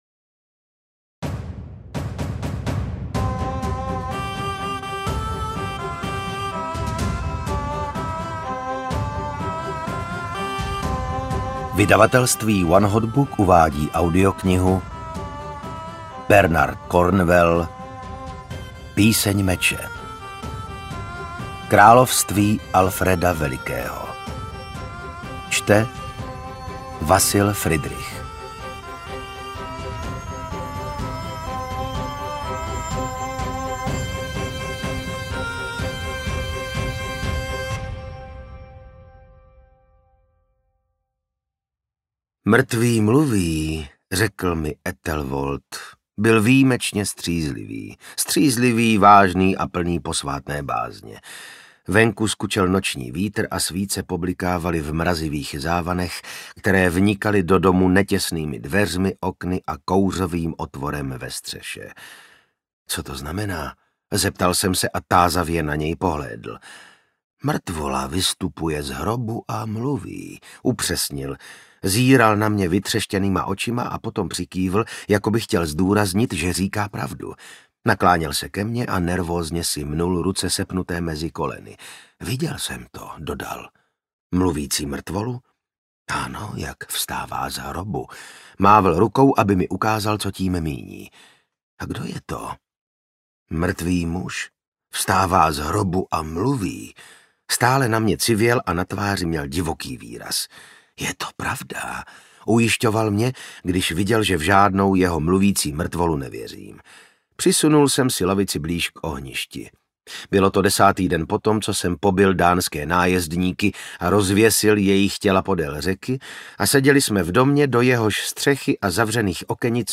Píseň meče audiokniha
Ukázka z knihy